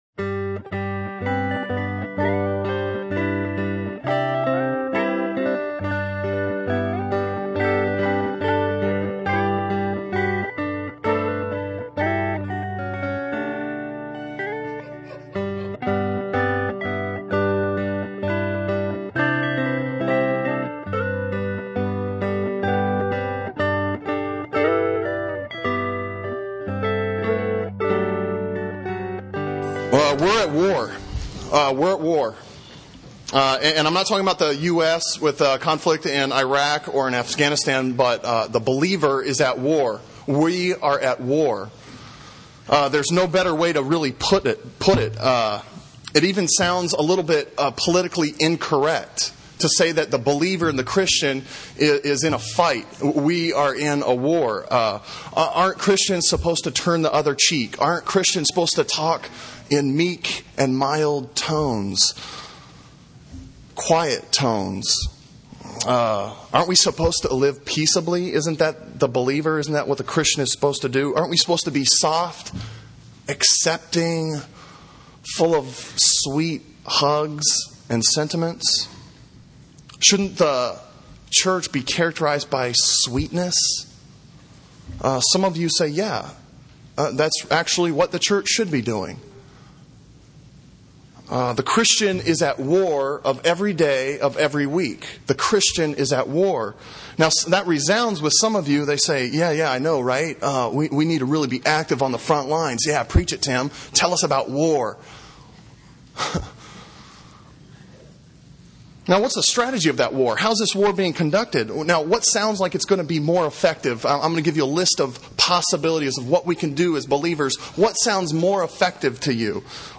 Sermon Audio from Sunday